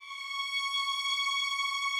Added more instrument wavs
strings_073.wav